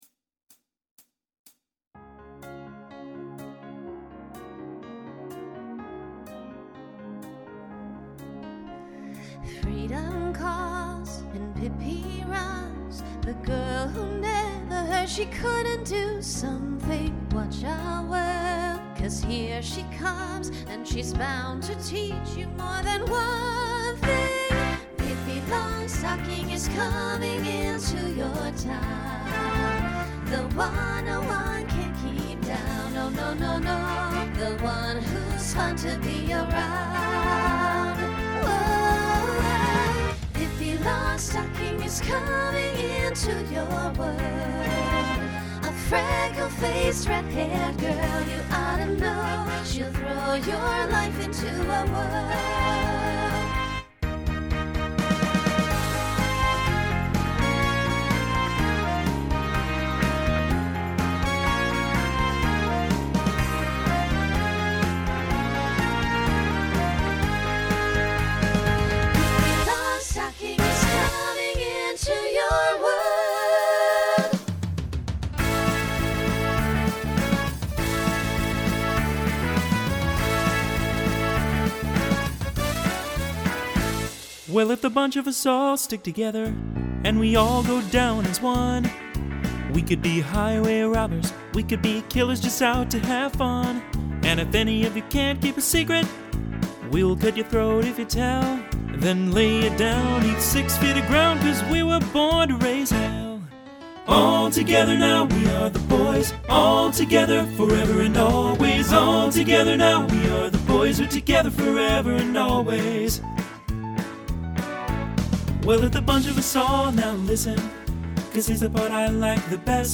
SSA
SATB
Genre Broadway/Film Instrumental combo
Transition Voicing Mixed